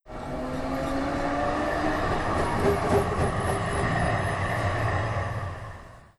Exemples à partir d’un son de train :